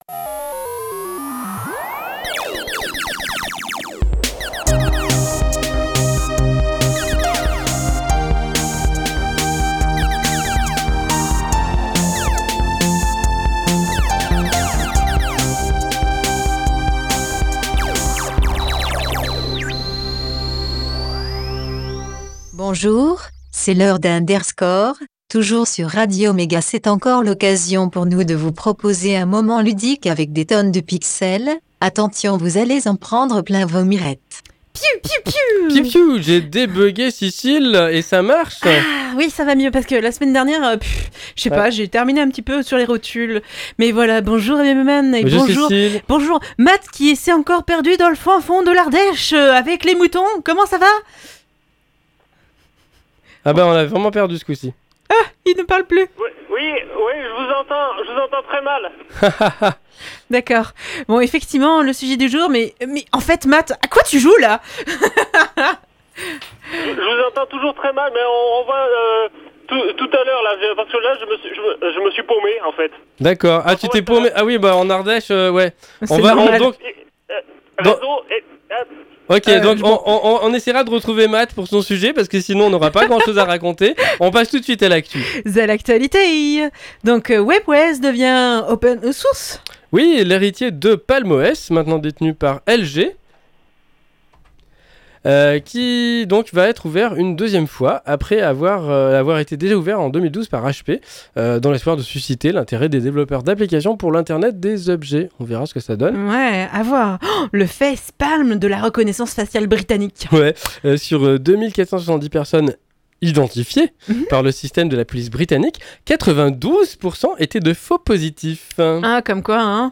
A Boy and his Blob De l'actu, une pause chiptune, un sujet, l'agenda, et astrologeek !